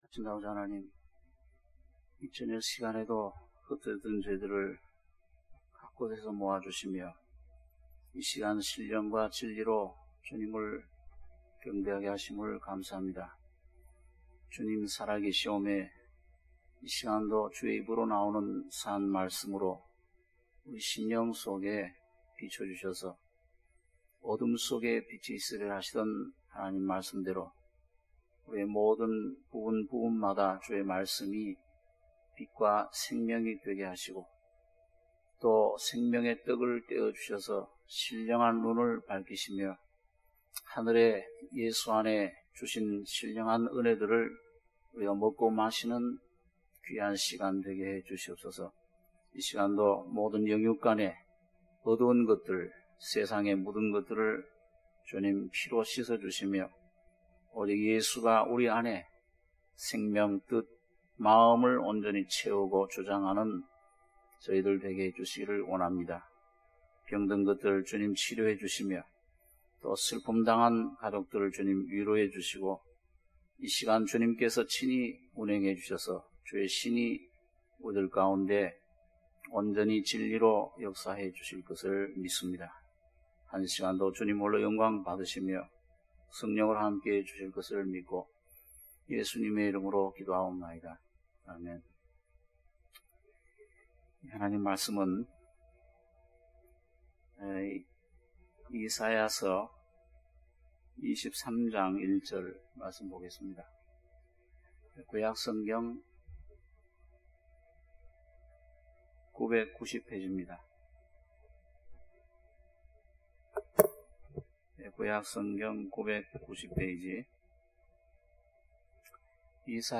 수요예배 - 이사야 23장 1-10절